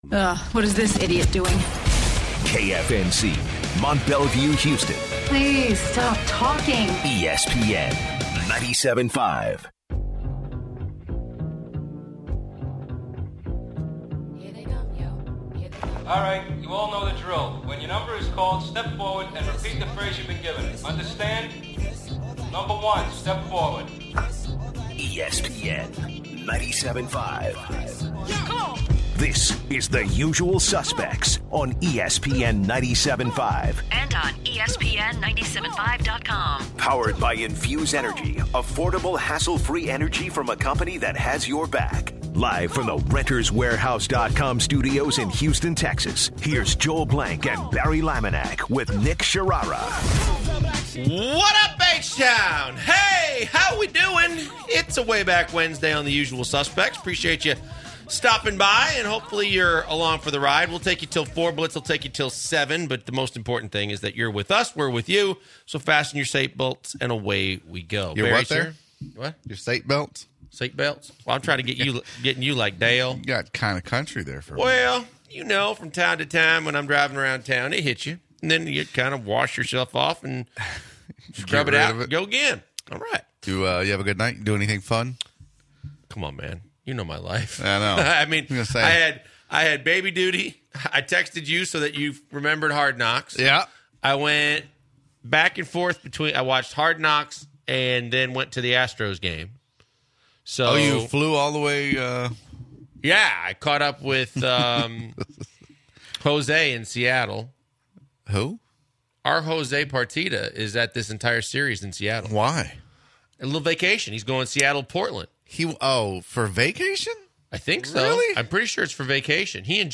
The Usual Suspects kick off the first hour talking babysitting woes. They take calls from listeners. They recap the latest episode of HBO’s Hard Knocks featuring the Cleveland Browns.